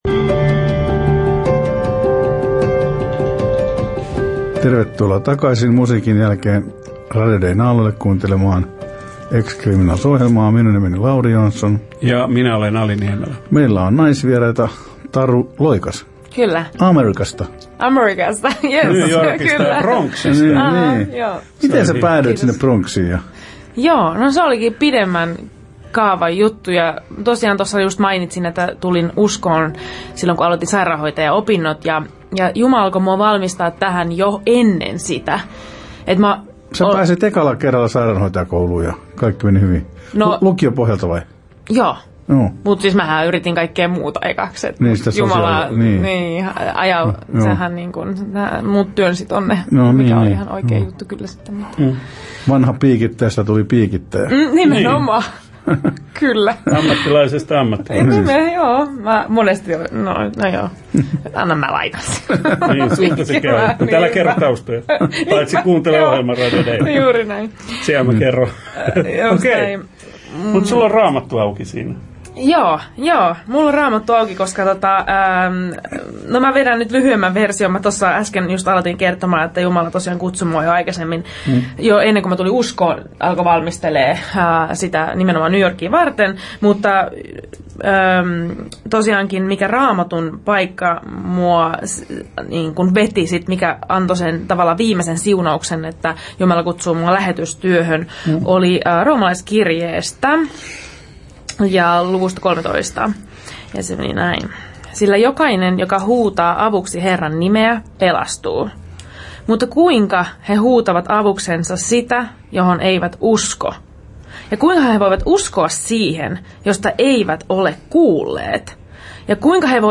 Kuuntele haastattelu: Osa I: